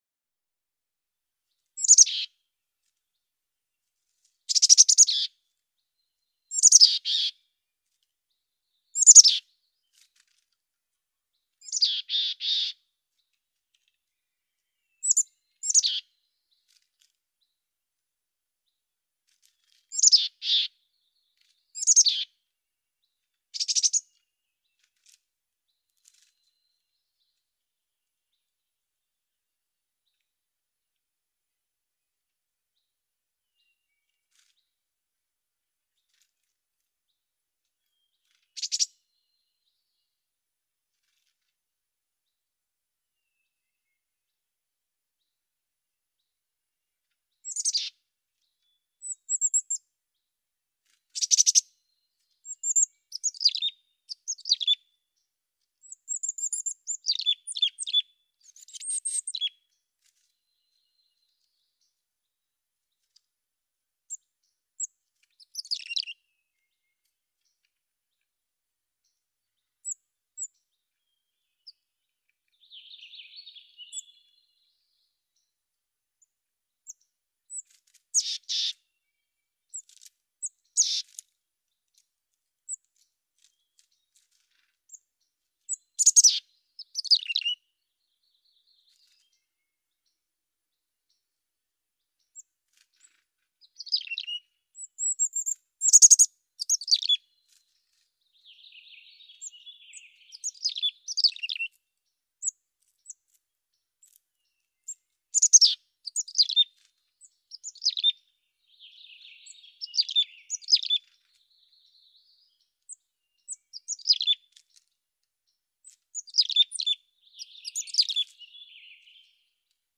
Chickadee, Mountain Chirps. Single Bird Chirps Answered In The Distant Background. Also Some Slight Rustling Movement. Medium Perspective.